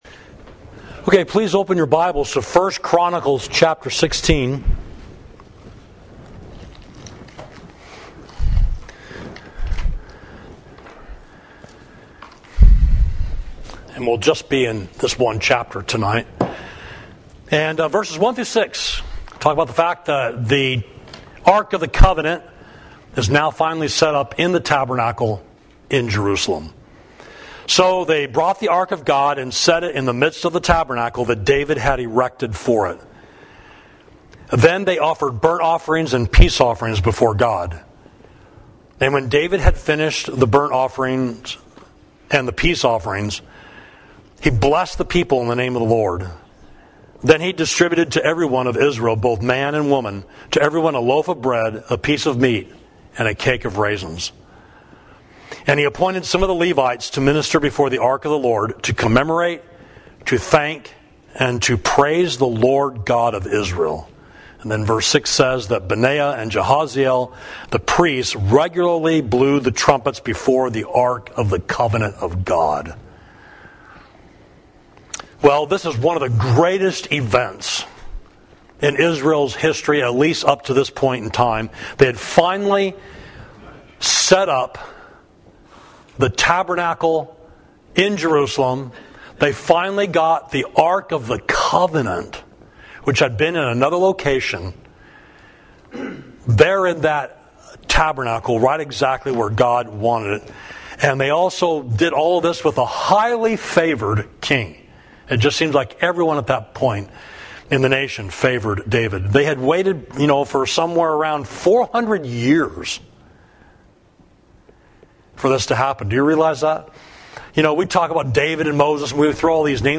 Sermon: Give Thanks to the Lord for All His Wonders Toward Us